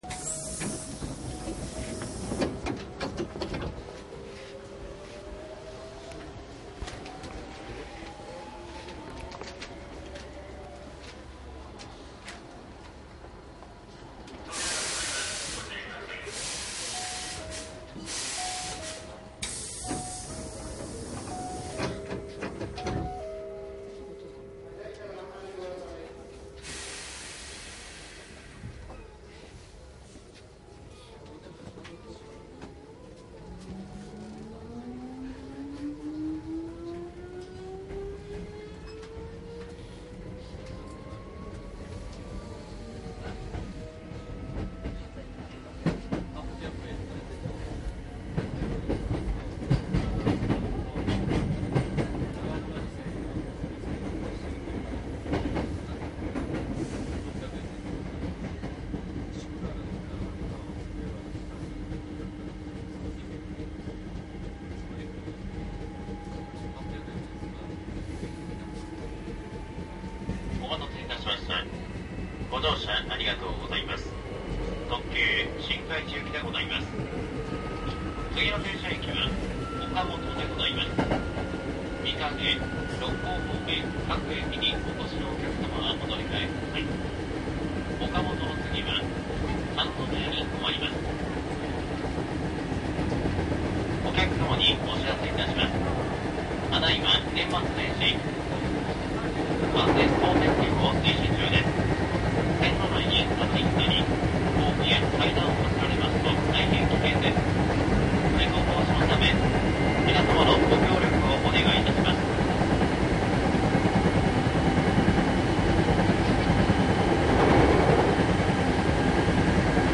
内容は阪急電車  特急 7000系走行音 CD ♪
梅田～西宮北口～新開地の走行音です。
■【特急】西宮北口→新開地 7612
マスター音源はデジタル44.1kHz16ビット（マイクＥＣＭ959）で、これを編集ソフトでＣＤに焼いたものです。